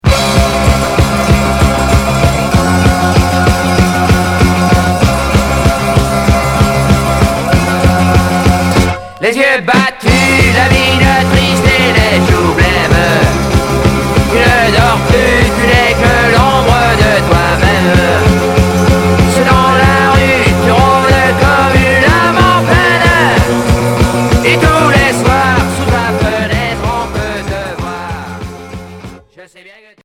Rock punk Deuxième 45t